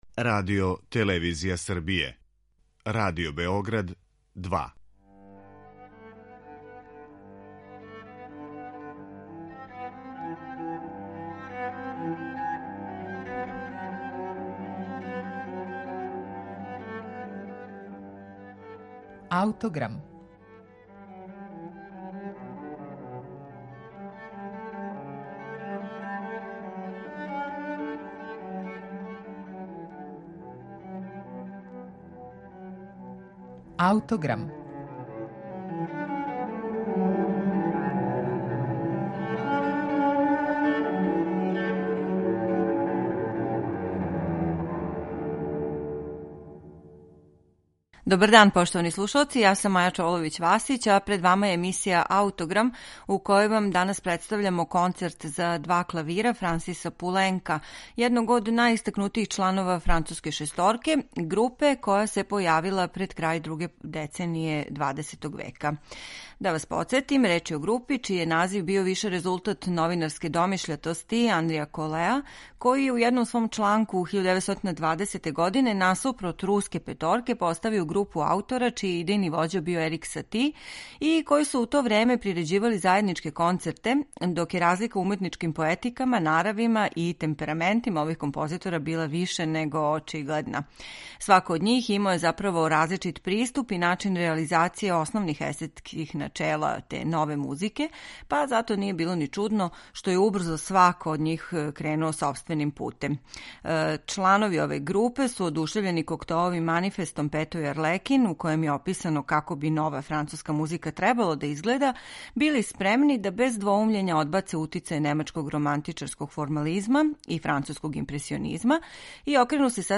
Концерт за два клавира